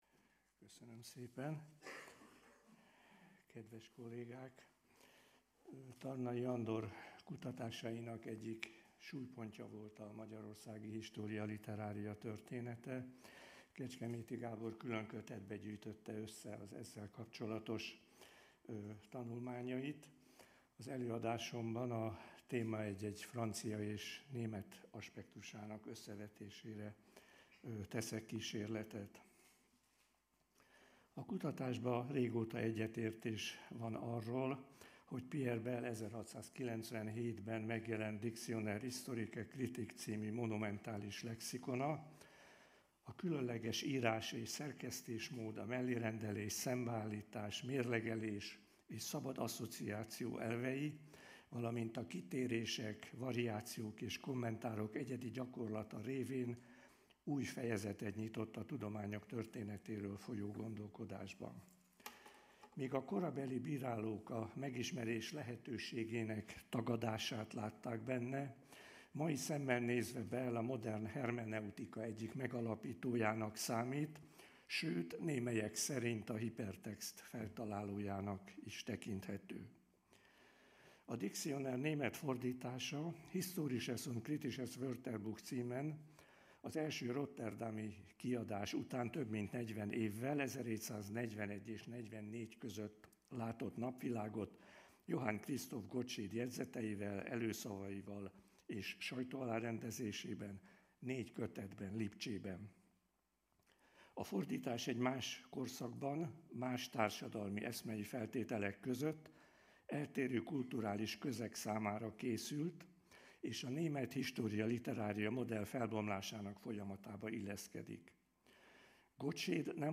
Tizenegyedik ülés